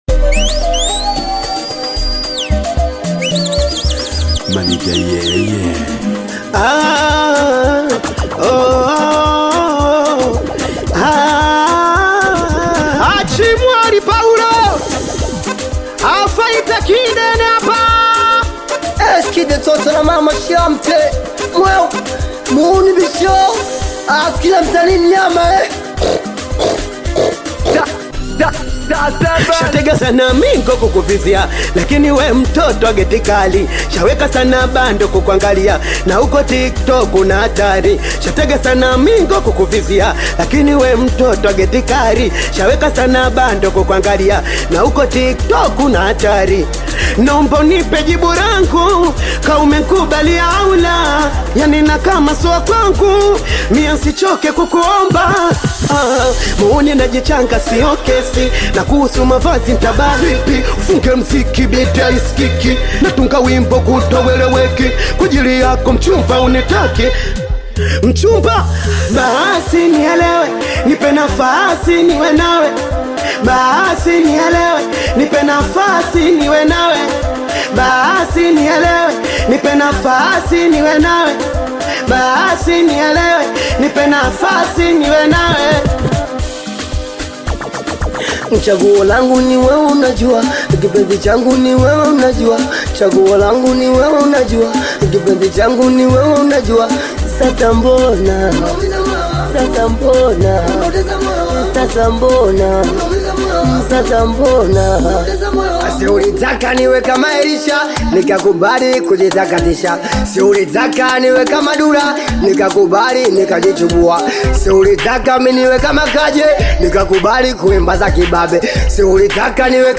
Singeli music track